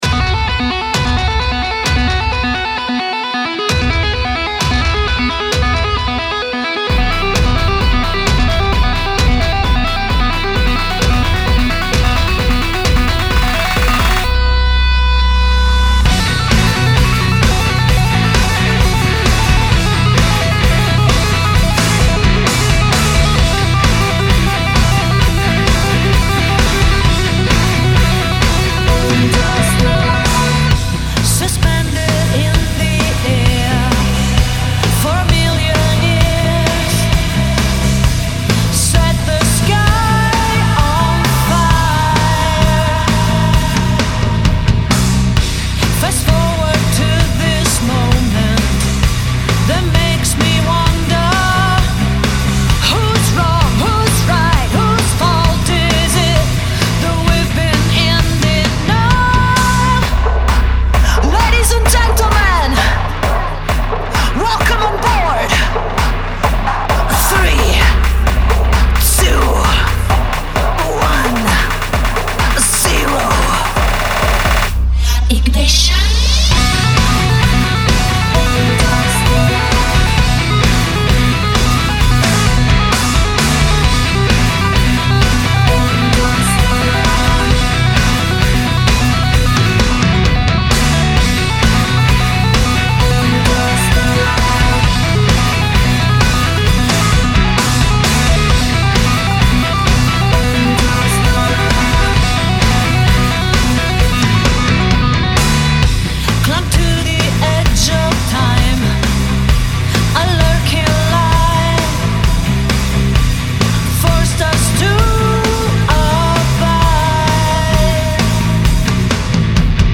Vocals
Guitars, Bass, Keyboard, Programming
Drums